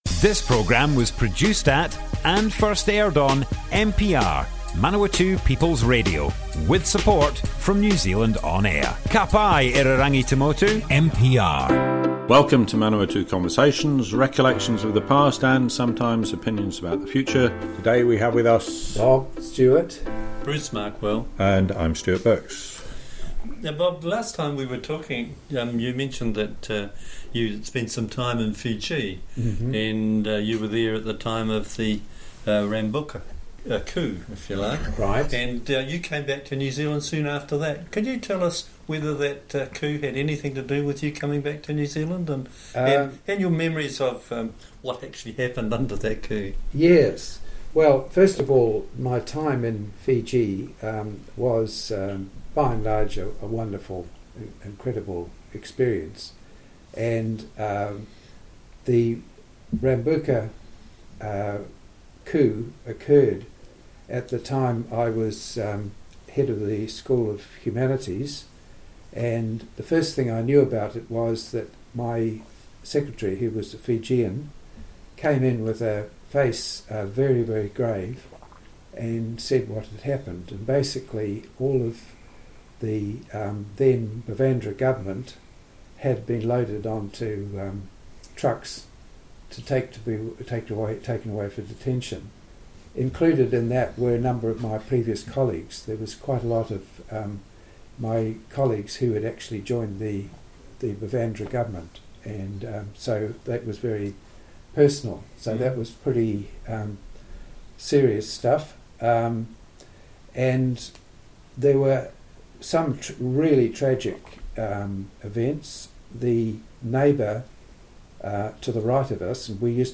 Manawatu Conversations More Info → Description Broadcast on Manawatu People's Radio 26th April 2022.